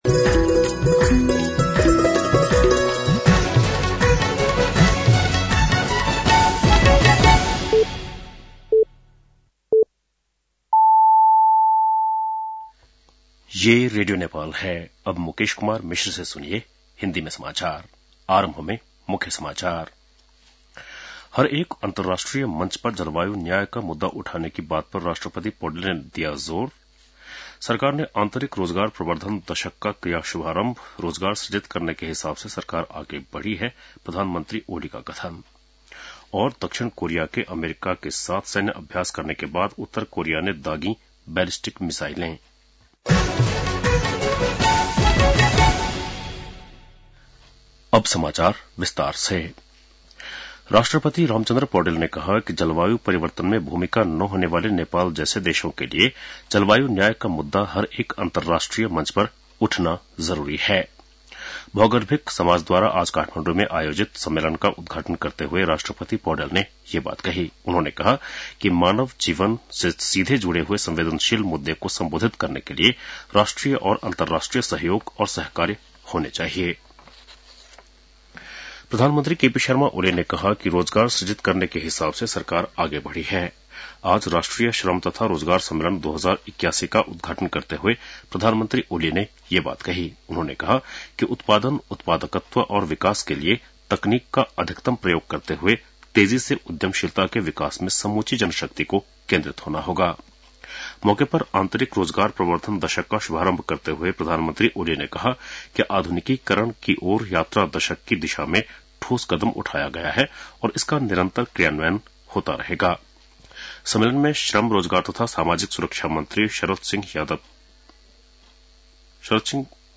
बेलुकी १० बजेको हिन्दी समाचार : २७ फागुन , २०८१